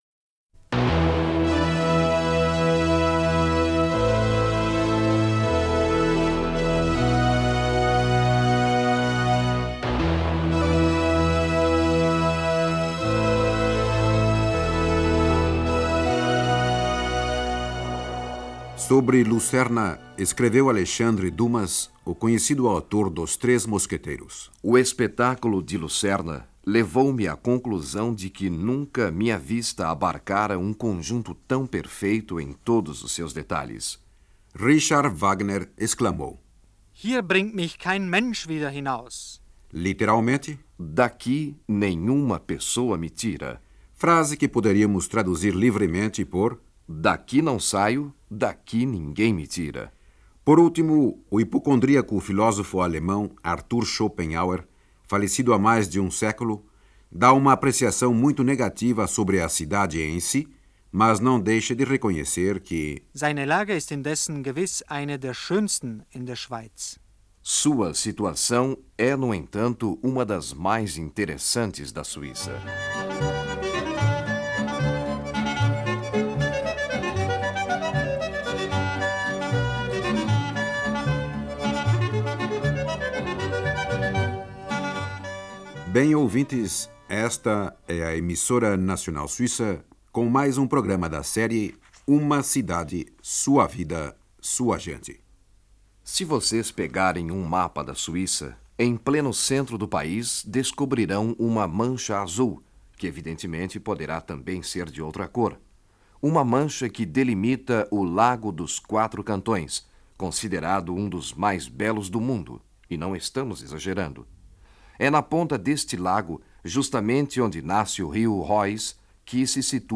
Uma passeio de rádio à Lucerna...em 1977